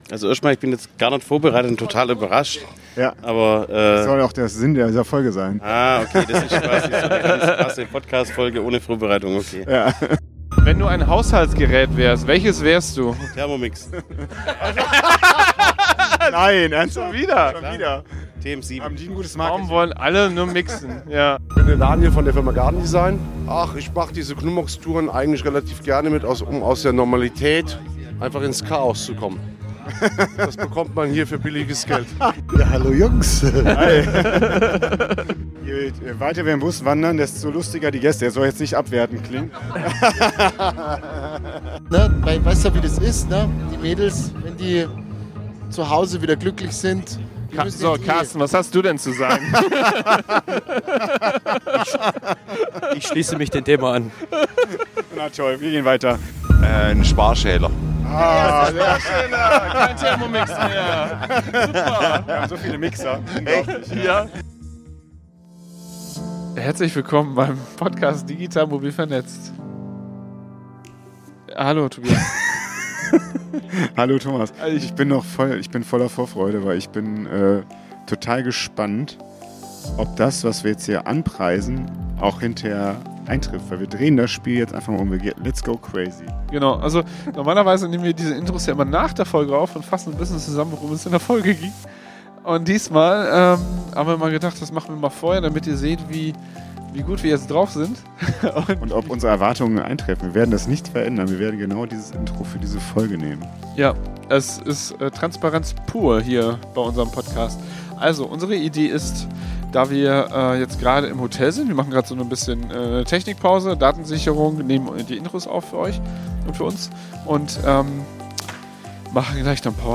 Beschreibung vor 5 Monaten Heute gibt es: - so viele Schnellfragen wie noch nie zuvor - so viele Gäste wie nie zuvor - so viel Chaos wie nie wieder Wir haben auf dem Weg vom Hotel in Porec zum Steinbruch direkt im Bus aufgenommen. Die Kameras haben uns leider im Stich gelassen, aber die Mikrofone haben uns weiterhin beste Dienste geleistet! Ihr dürft diese Busfahrt genießen und mit uns laut mitlachen.